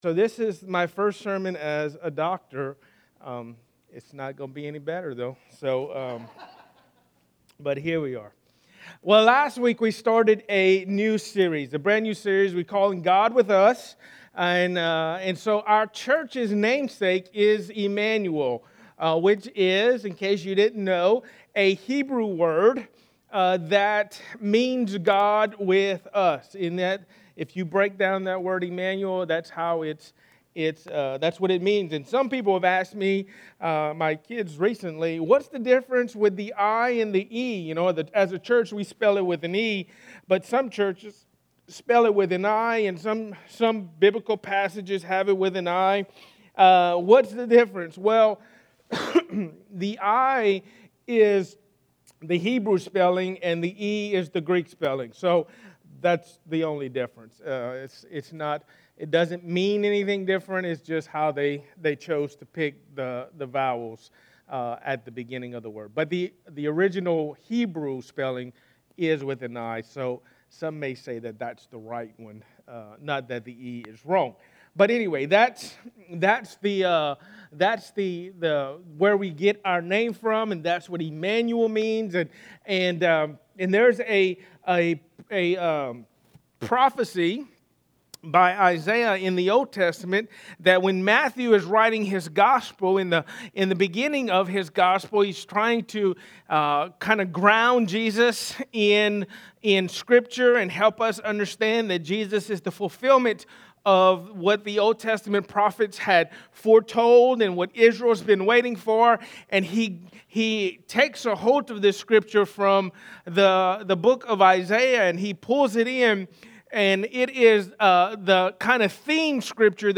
In this collection, the sermons are stand alone and not part of a particular series